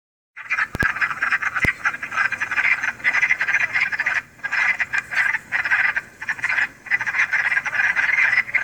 The calls of the male Wood Frog sounds just like quacking ducks, and right now they’re busy doing their best imitations of the feathered versions!
WoodFrog.m4a